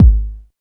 81 KICK 2.wav